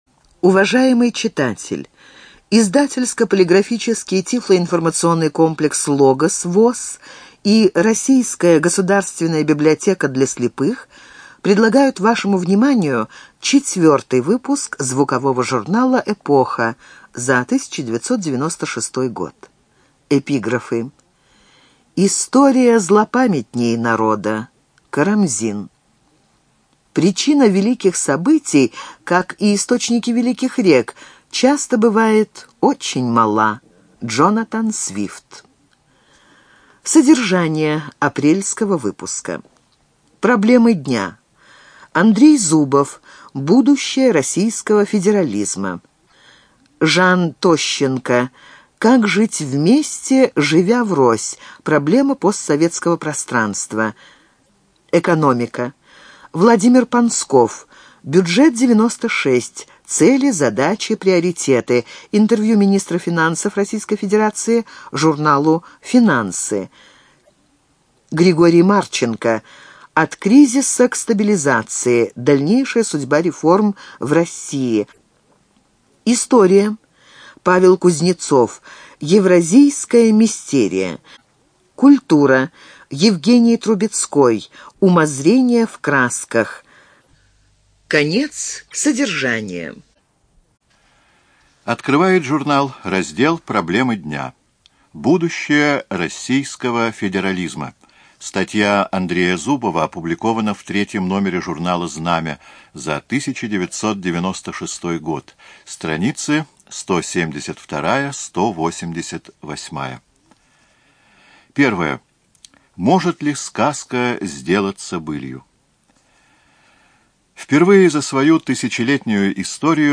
ЖанрПублицистика
Студия звукозаписиЛогосвос